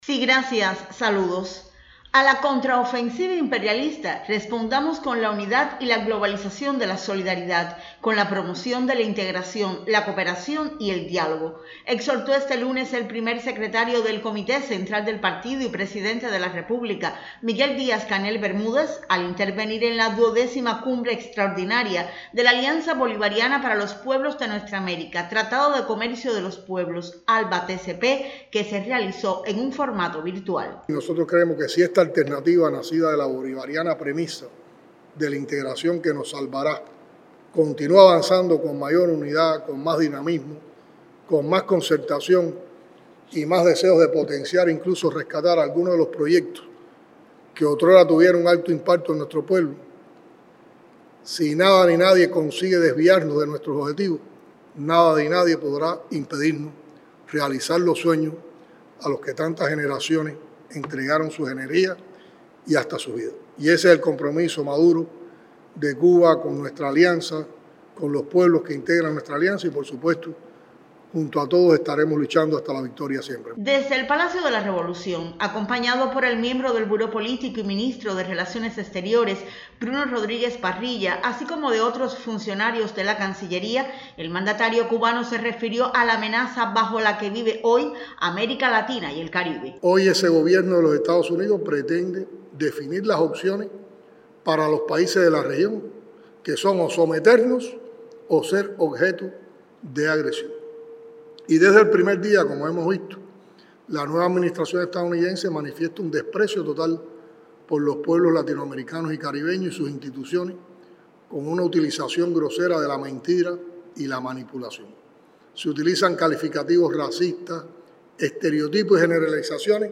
La XII Cumbre Extraordinaria de los Pueblos y Gobiernos de los países del ALBA-TCP (Alianza Bolivariana para los pueblos de Nuestra América-Tratado de Comercio de los Pueblos), tuvo lugar en la mañana de este lunes, en formato virtual, y contó con las reflexiones del Presidente de la República de Cuba, Miguel Díaz-Canel Bermúdez.